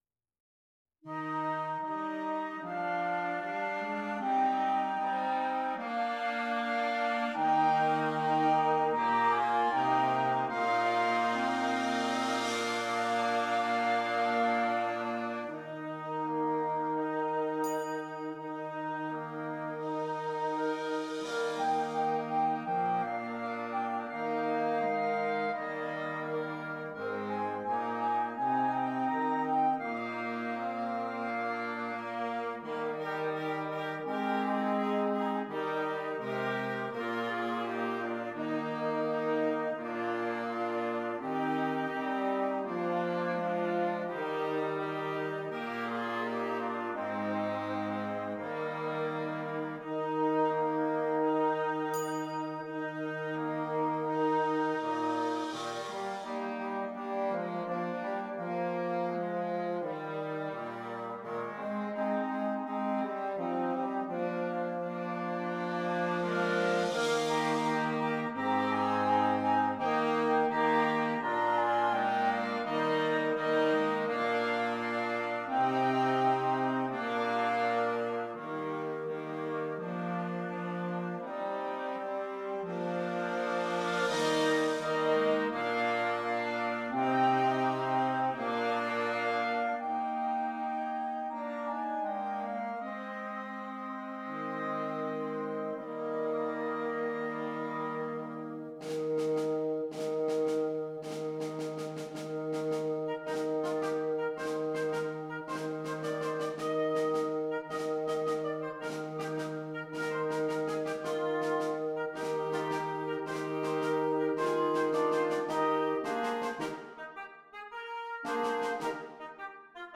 Interchangeable Woodwind Ensemble
Dutch Folk Songs
This is a fast movement in 6/8.
PART 1 - Flute, Oboe, Clarinet
PART 3 - Clarinet, Alto Saxophone, F Horn
PART 5 - Bass Clarinet, Bassoon, Baritone Saxophone
Optional Percussion